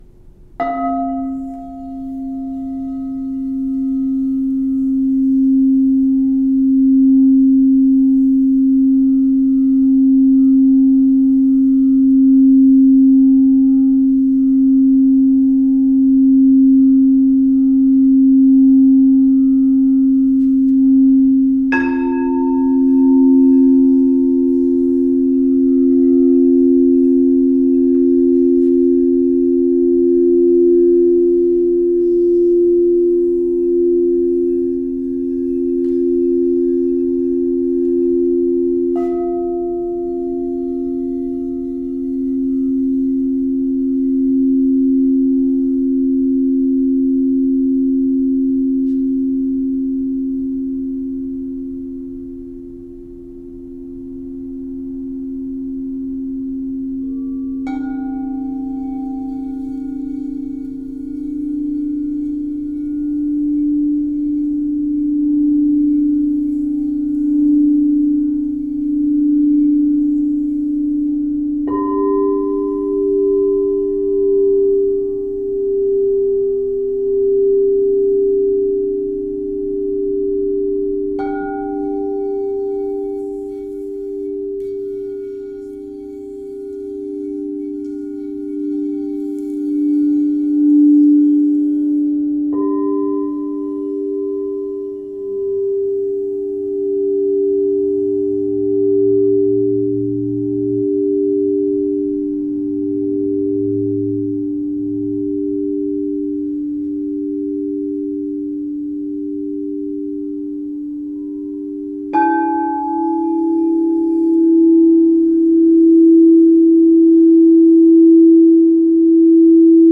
Unlocking Inner Harmony: The Transformative Power of Crystal Singing Bowls in Meditation
These mesmerizing instruments, renowned for their rich, harmonic tones and healing vibrations, are becoming increasingly sought after as a means to elevate and deepen one's meditation practice.
The Ethereal Resonance of Crystal Singing Bowls: Originating from the ancient tradition of sound therapy, Crystal Singing Bowls produce exquisite tones that resonate with the body's natural frequencies. Crafted from pure crushed quartz and other natural crystals, these bowls emit exceptional clarity and vibrancy vibrations, creating an immersive sonic experience that transcends the ordinary.